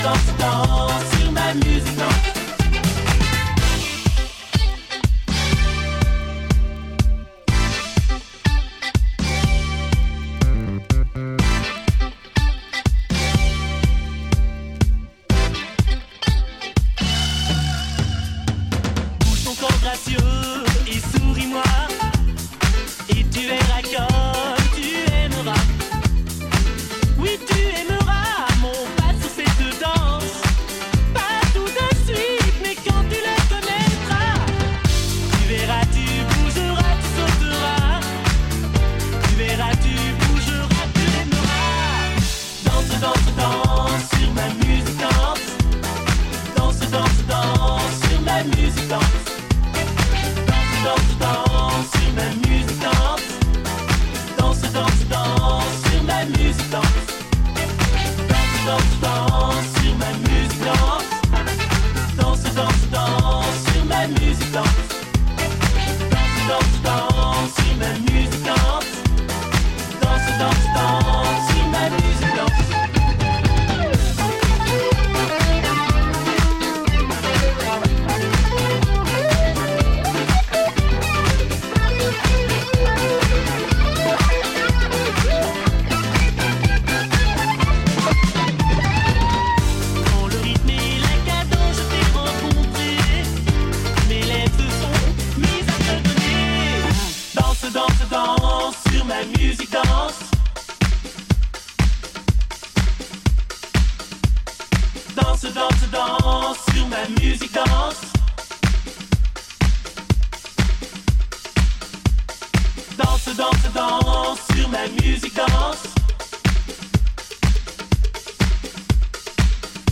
struts in a sunny groove built for open-air euphoria